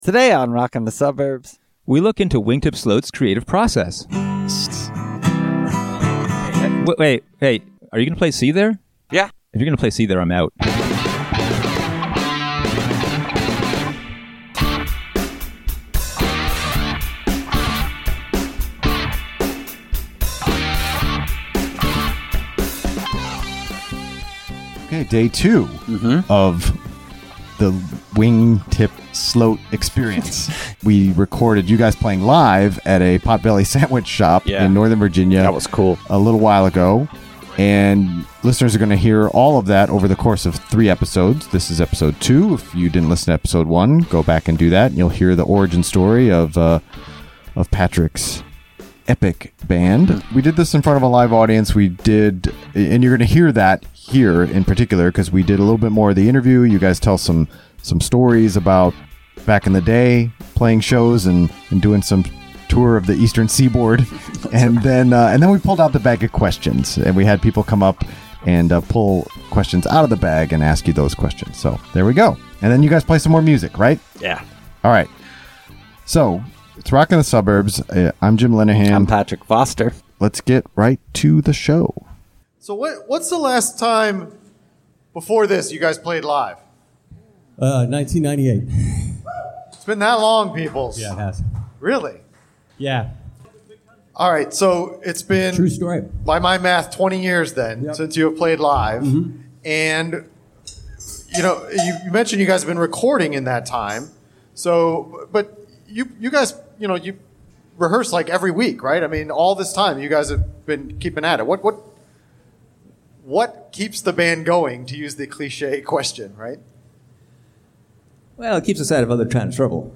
Interview and performance.